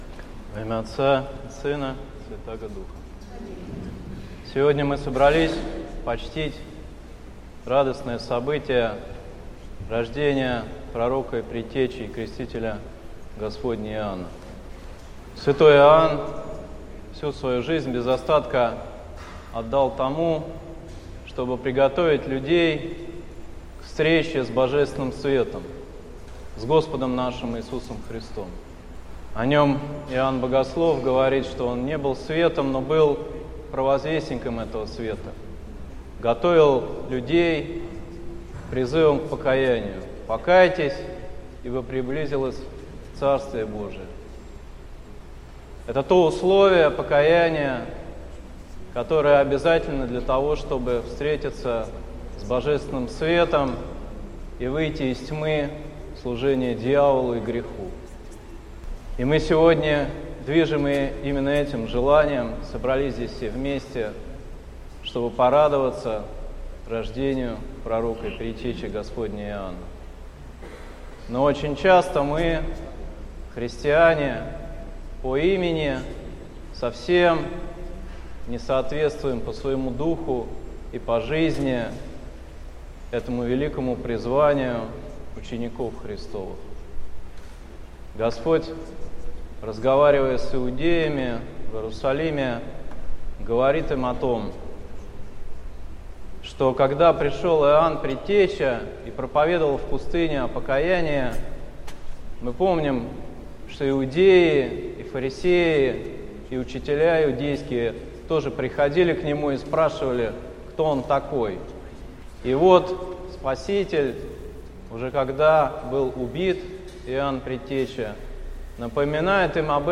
Слово на Рождество честнаго славного Пророка, Предтечи и Крестителя Господня Иоанна